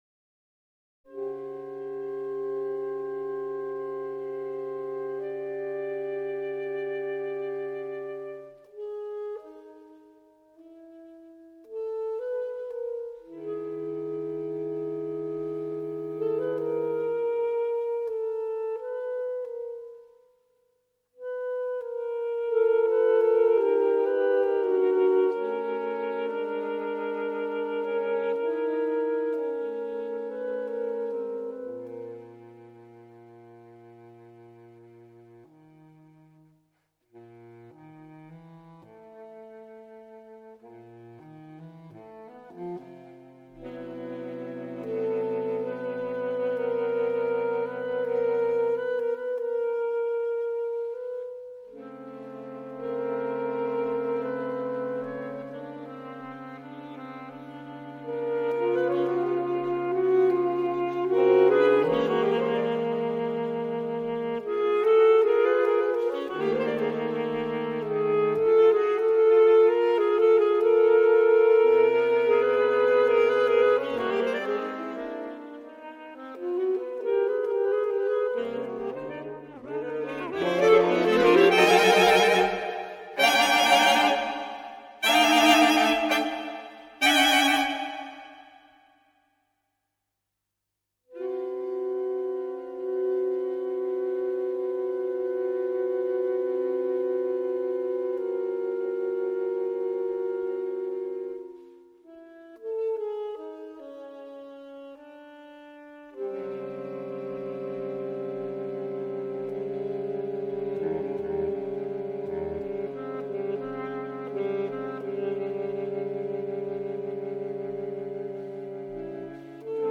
Saxophonquartett